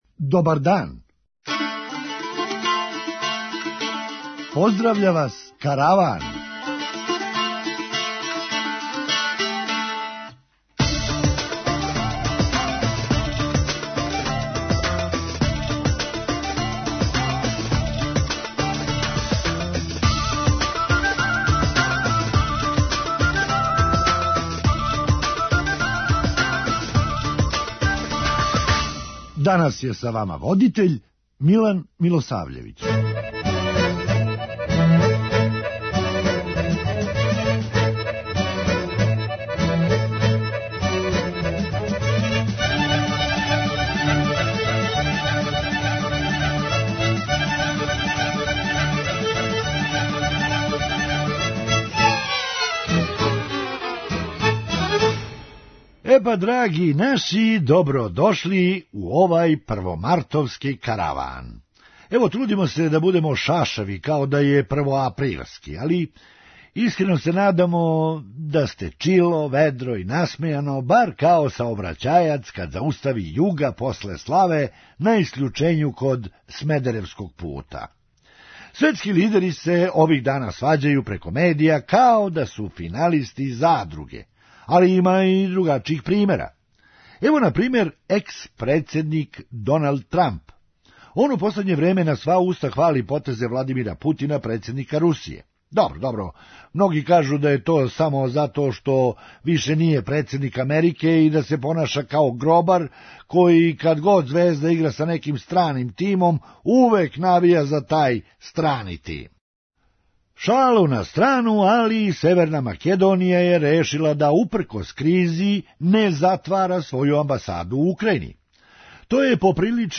Хумористичка емисија
Дакле, настављамо да купујемо чарапе и пешкире у Блоку 70. преузми : 8.98 MB Караван Autor: Забавна редакција Радио Бeограда 1 Караван се креће ка својој дестинацији већ више од 50 година, увек добро натоварен актуелним хумором и изворним народним песмама.